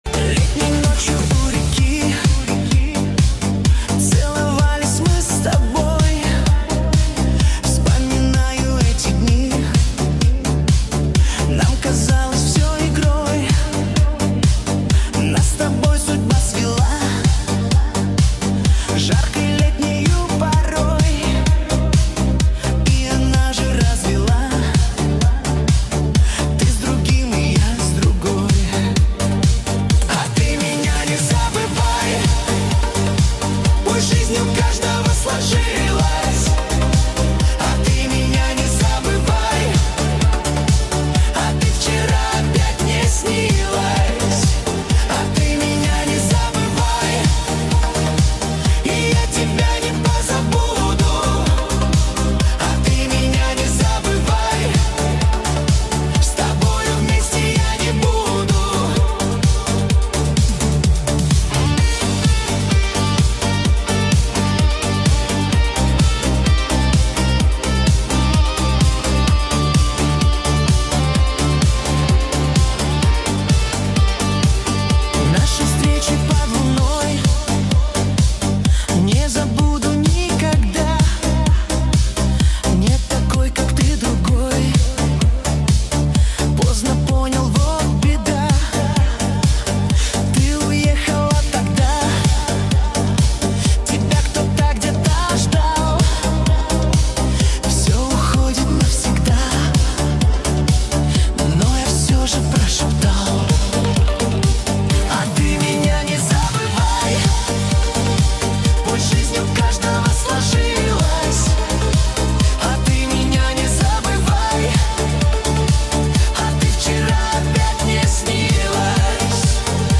Качество: 320 kbps, stereo